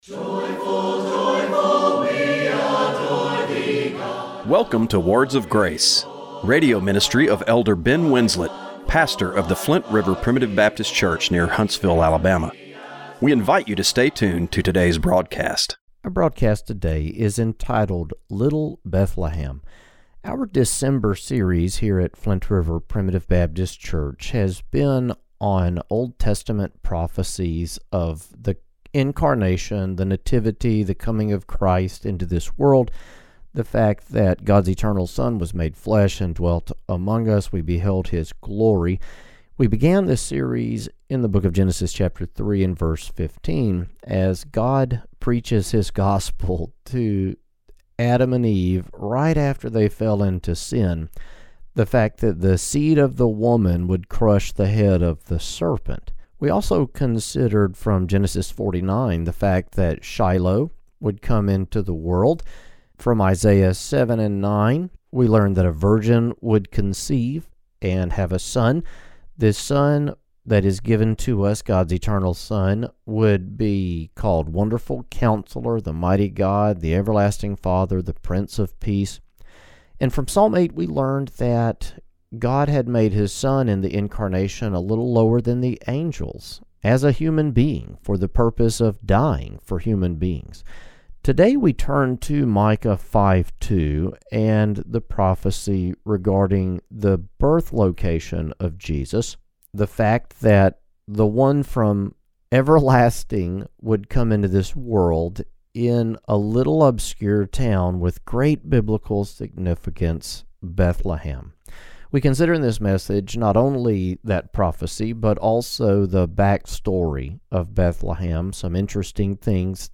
Radio broadcast for December 28, 2025.